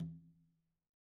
Conga-HitN_v1_rr1_Sum.wav